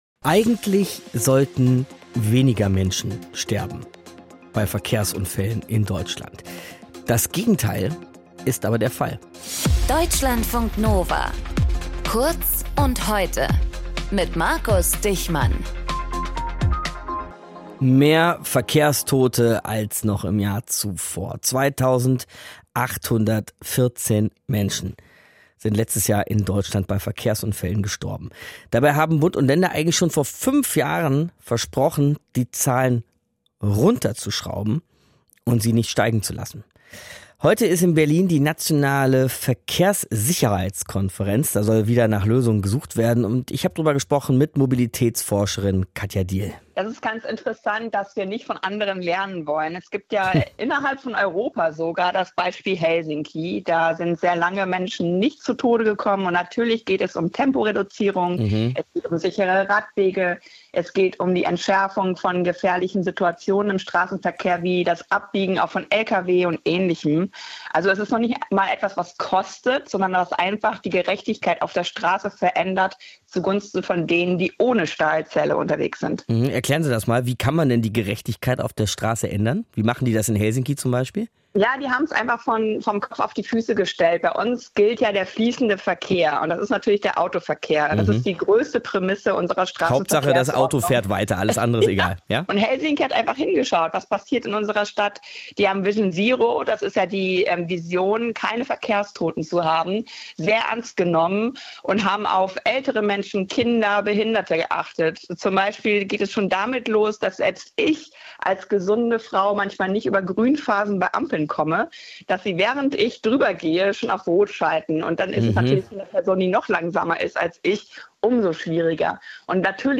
Moderator
Gesprächspartnerin